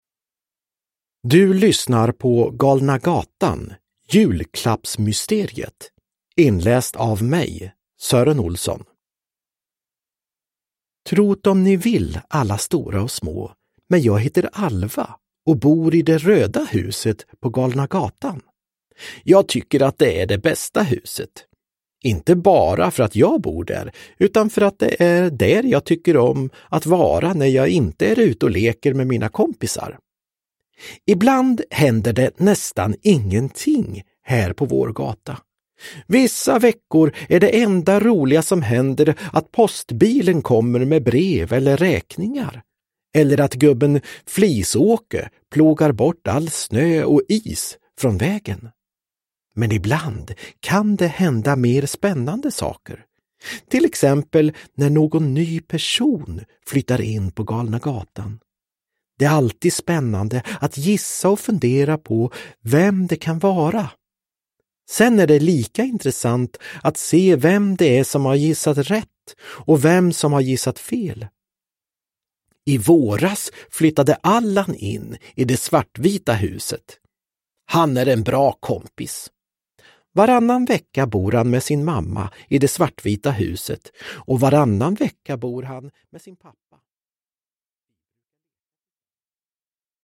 Galna gatan - Julklappsmysteriet – Ljudbok – Laddas ner
Uppläsare: Sören Olsson, Anders Jacobsson